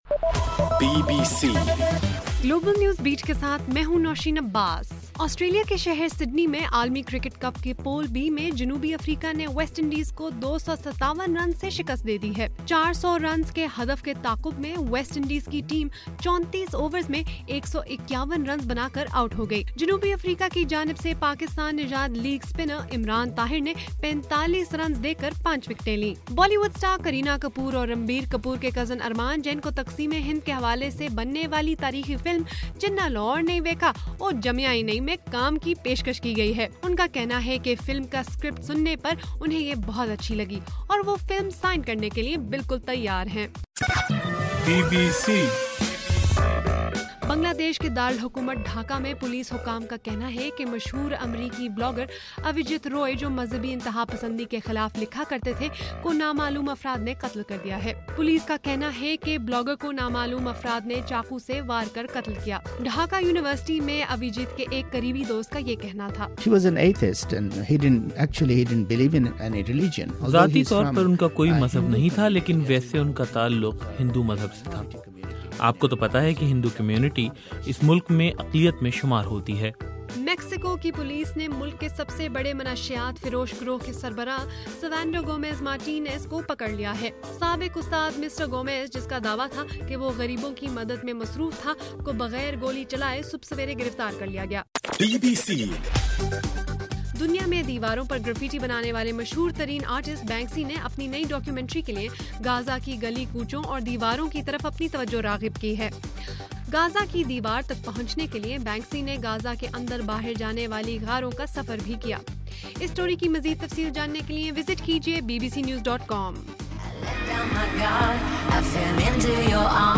فروری 27: رات 11 بجے کا گلوبل نیوز بیٹ بُلیٹن